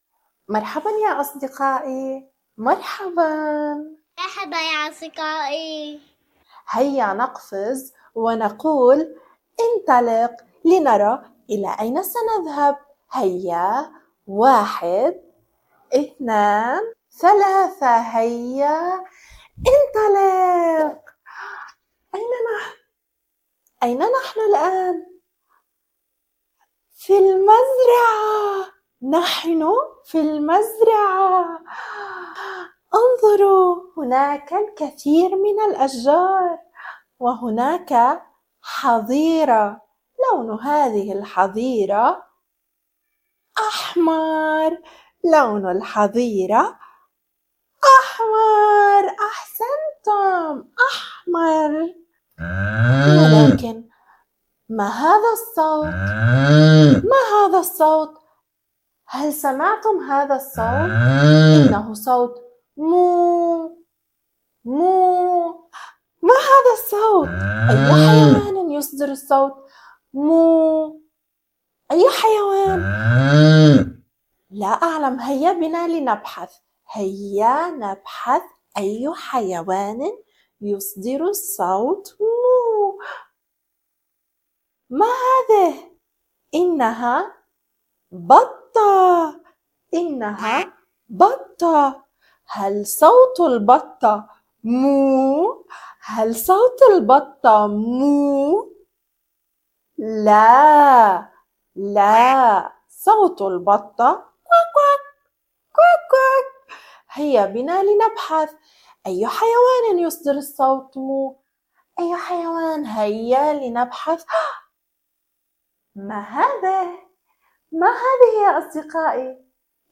Farm Animals Names & Sounds in Arabic for Kids أسماء و